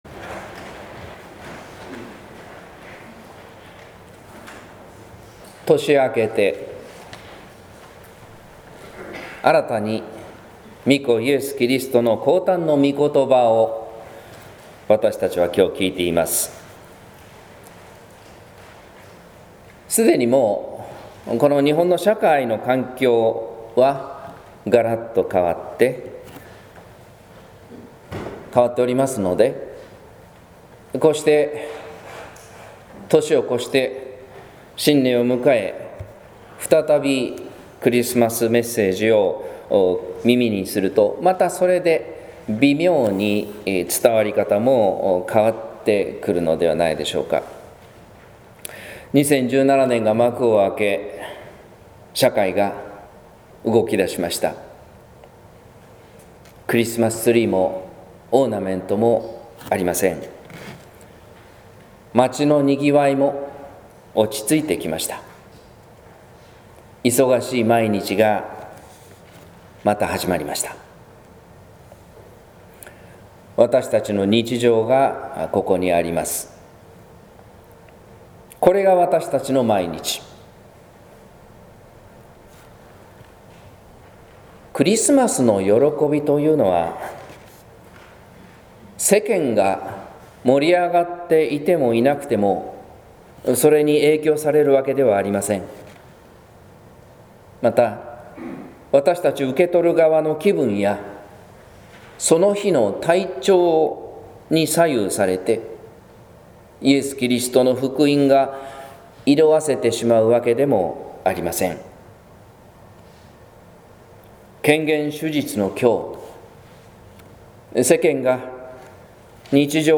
説教「かすかな光と出会う」（音声版）